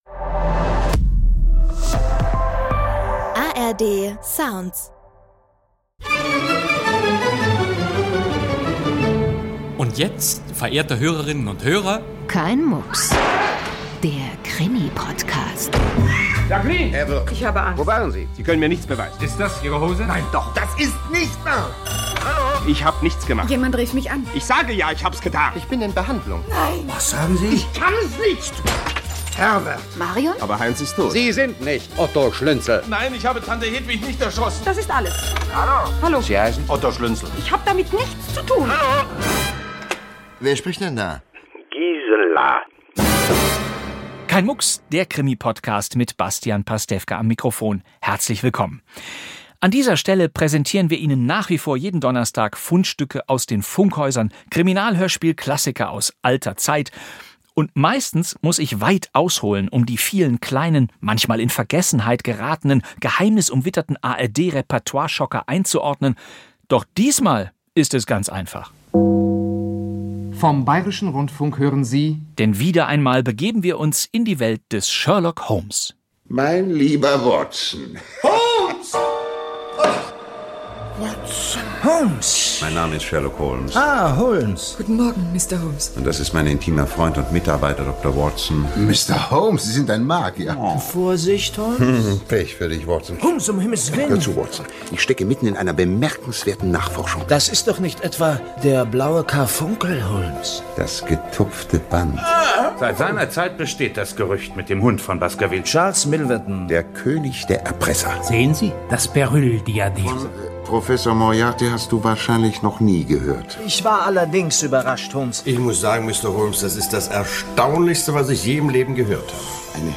Sherlock Holmes spannt aus. Krimi-Podcast mit Bastian Pastewka ~ Kein Mucks!